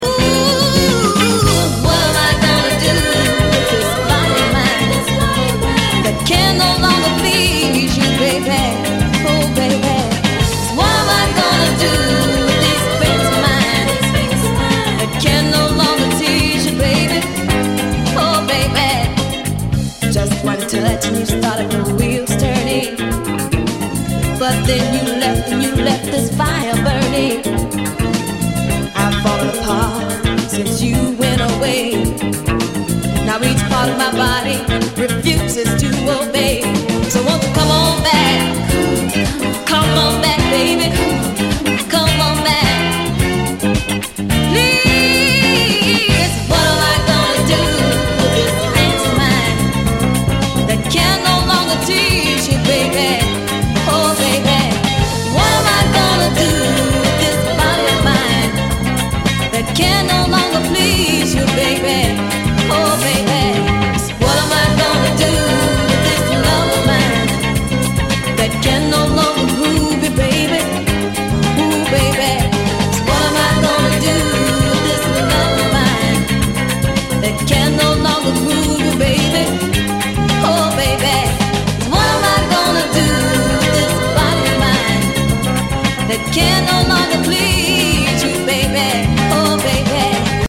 】1977年リリースのオリジナルは4,5万は下らない激レア・ファンキー・ソウル〜ディスコ・アルバムが正規復刻！